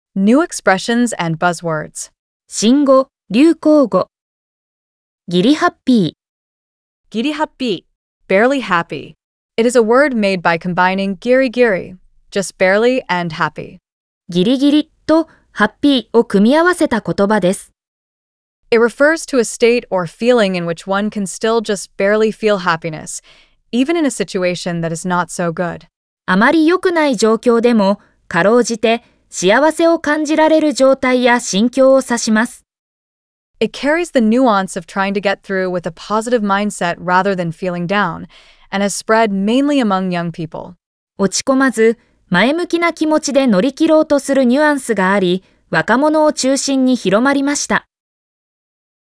🗣 pronounced: Giri happi-